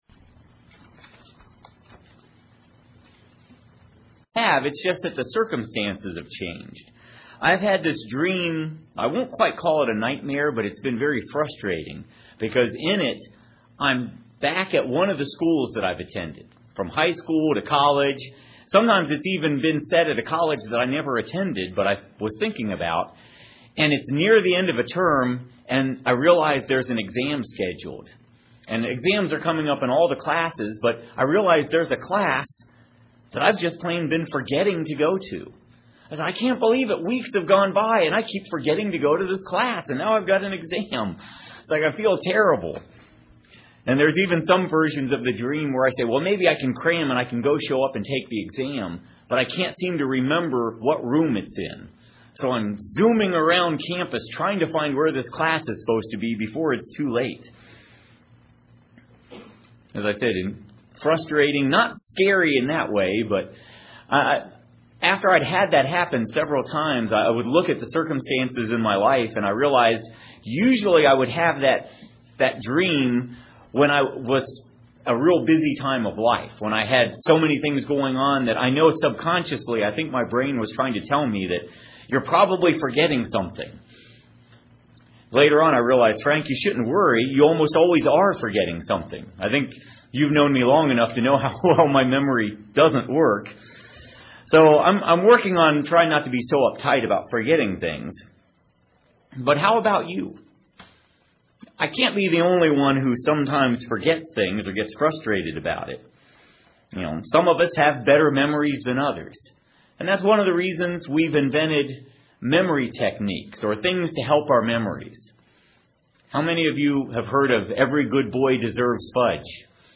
Find out in this sermon just how God did remember and is still keeping his promises today and in the future.